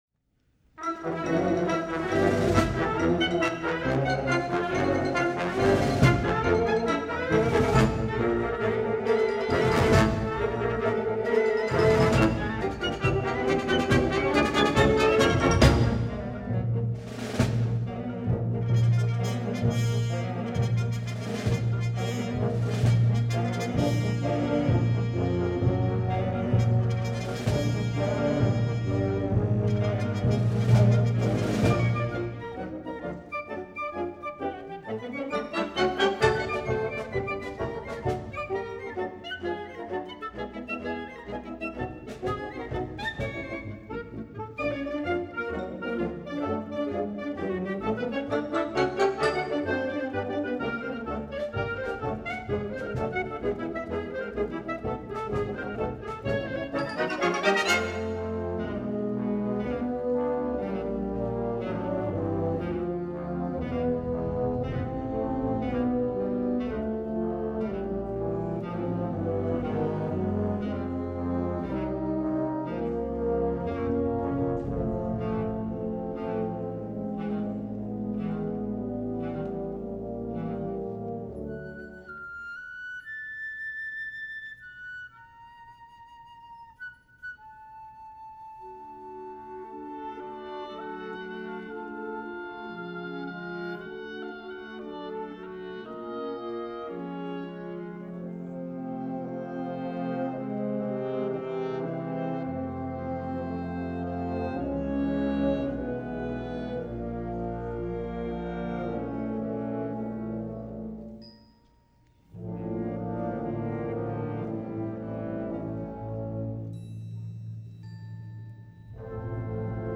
編成：吹奏楽